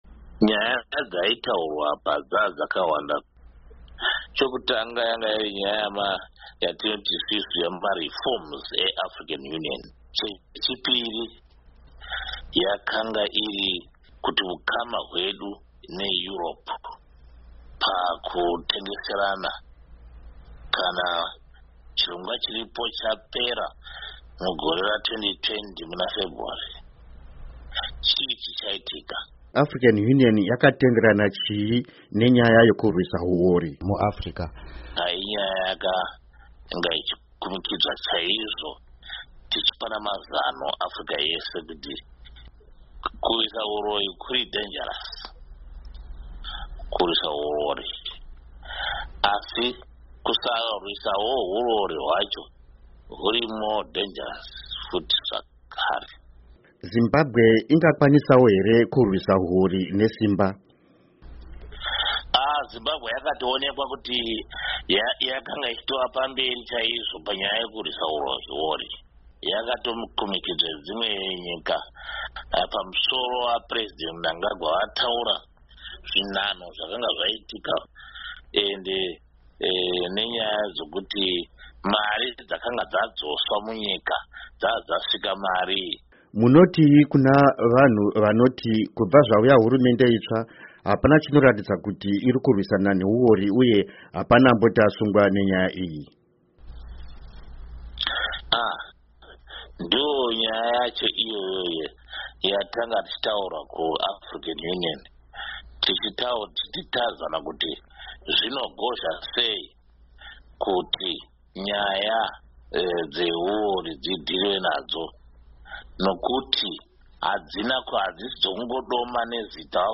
Hurukuro NaDoctor Sibusiso Moyo